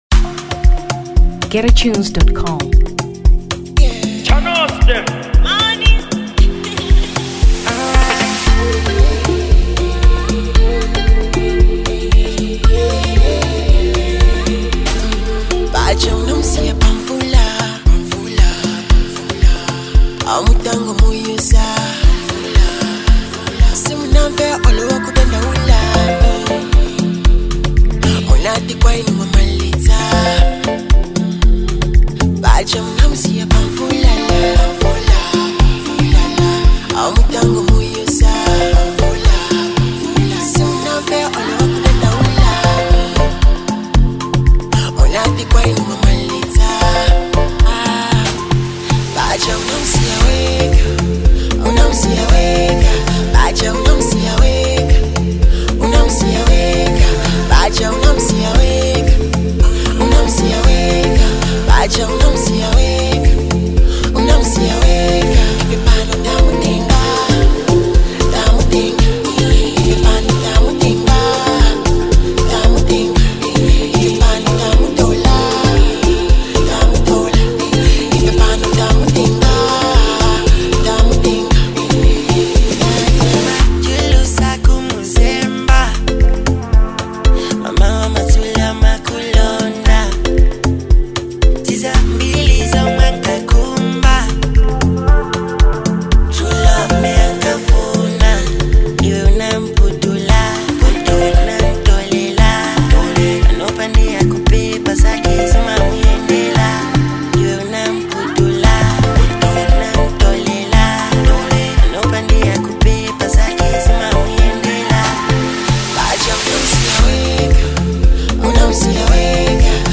Amapiano 2023 Malawi